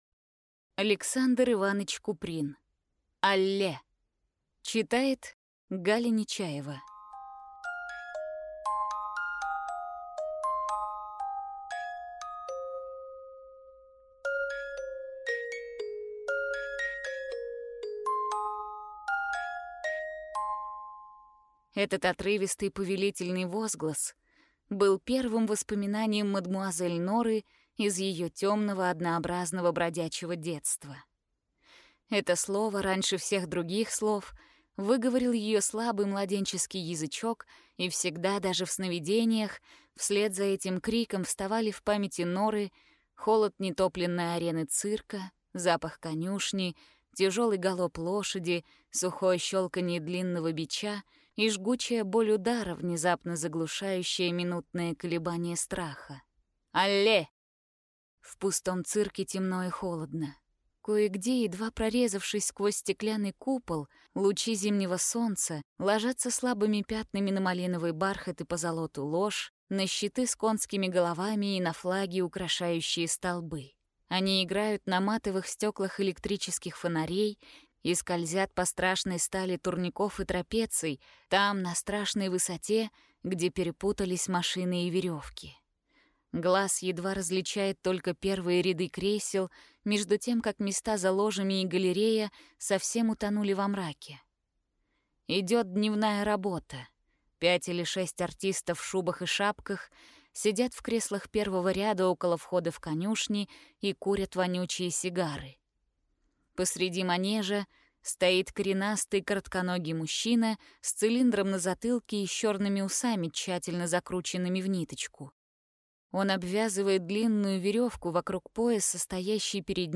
Aудиокнига Allez!